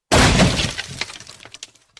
coffin_shatter.ogg